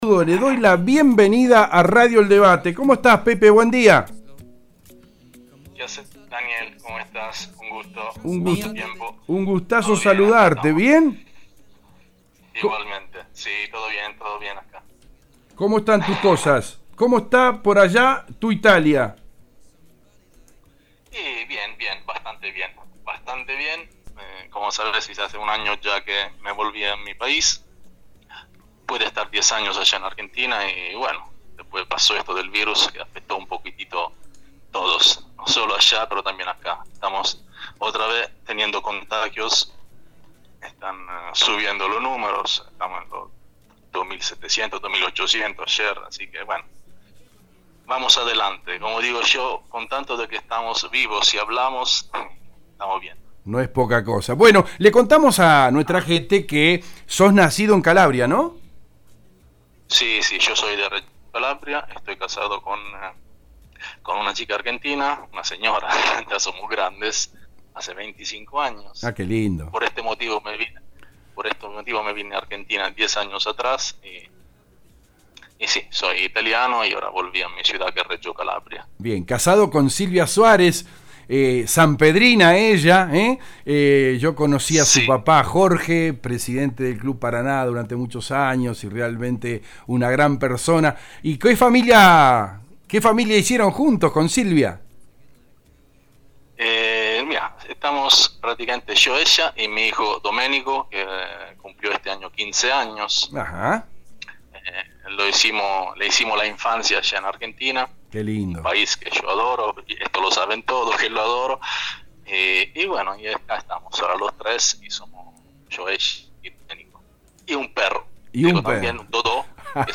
(Nota radial del 8/10/20)